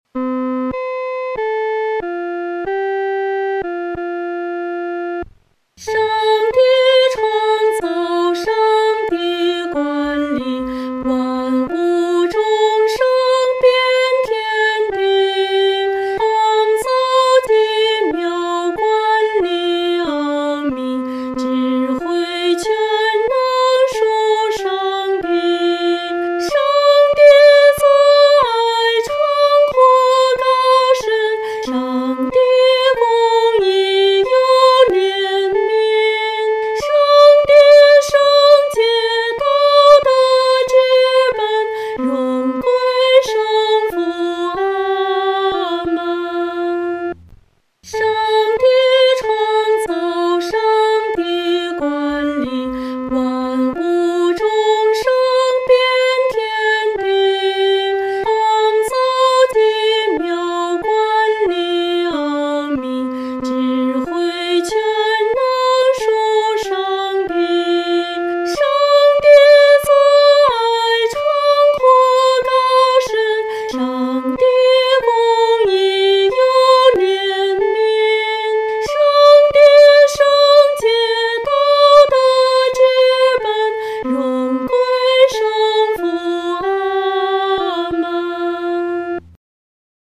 合唱
女高
本首圣诗由网上圣诗班 (石家庄二组）录制